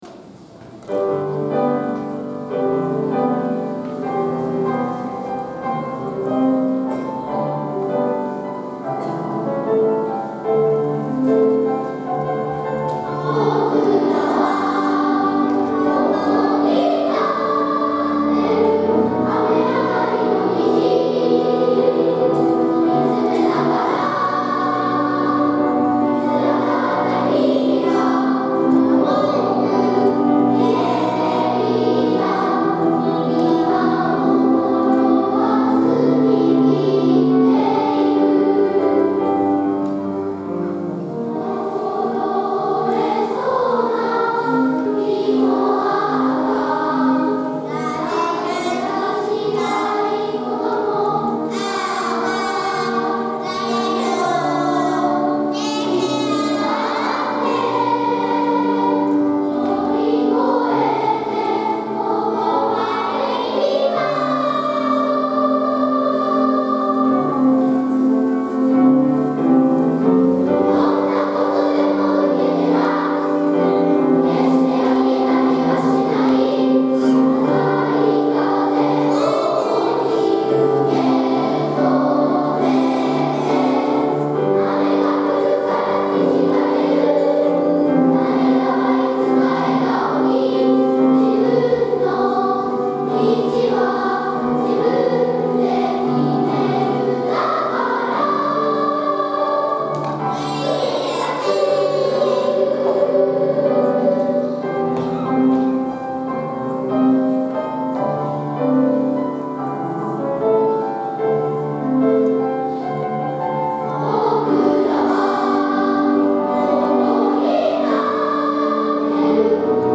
今日は第１４回大空ふれあいコンサートです！
３・４年生（中学年チーム）　2部合唱「
合唱の最後には、一人ひとりの決意を込めた「受けて立つ！！」という力強いメッセージを届けることができました！！